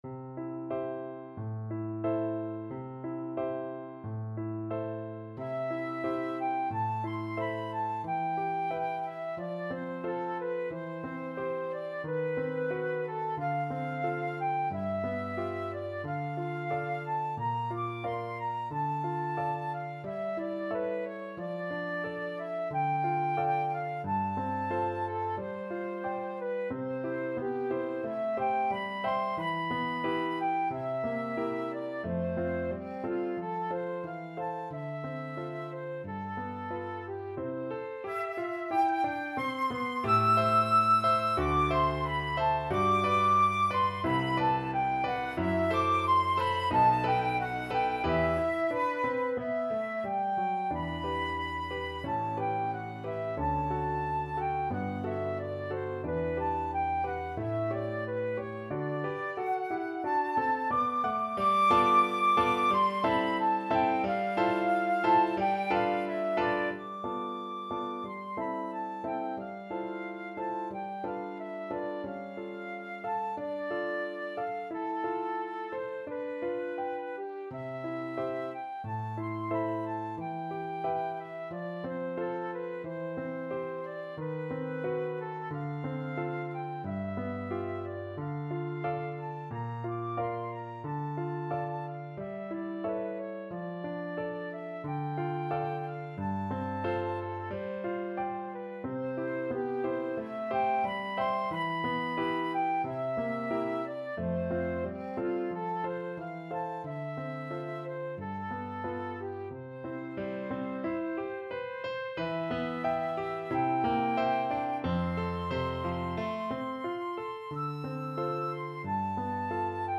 Flute
C major (Sounding Pitch) (View more C major Music for Flute )
~ = 100 Allegretto con moto =90
2/4 (View more 2/4 Music)
Classical (View more Classical Flute Music)